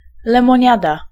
Ääntäminen